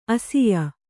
♪ asiya